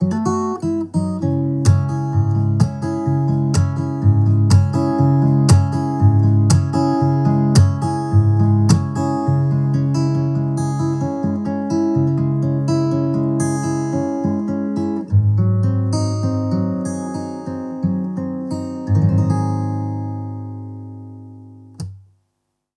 Échantillons sonores Audio Technica AE-2500
Audio Technica AE-2500 mikrofon - gitara akustyczna